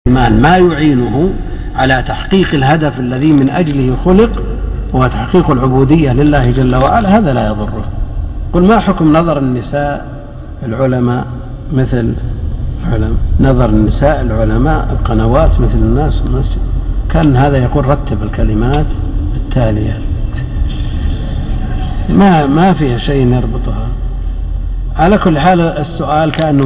طبعاً هو مقطع طريف ، بداية المقطع نهاية الشيخ من جواب لسؤال سابق ومن ثم يأتي المقطع الذي قال فيه الشيخ أو عشان ما احرق عليكم المقطع حمل واسمع
رابط تحميل المقطع الطريف في نهاية المحاضرة في الاسئلة